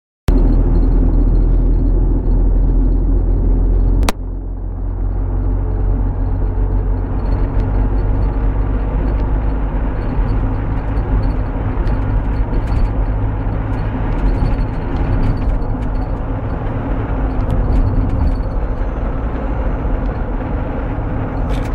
How's this for a squeaky taxi?